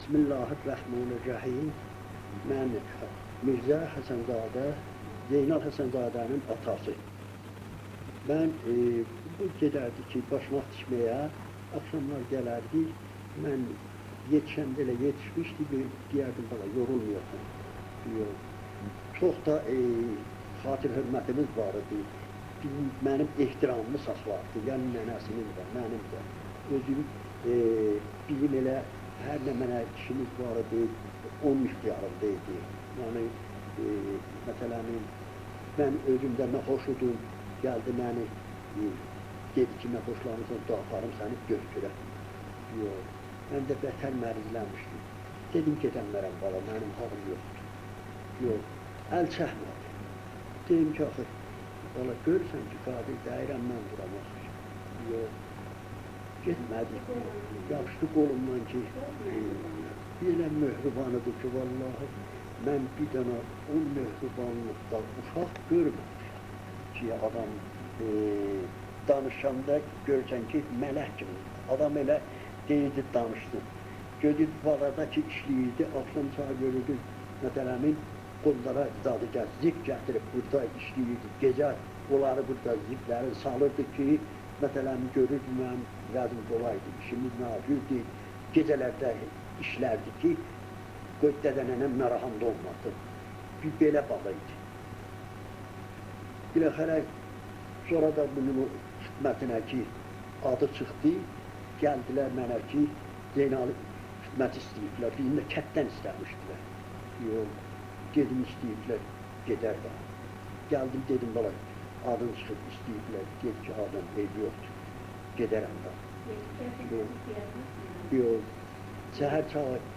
صوت / مصاحبه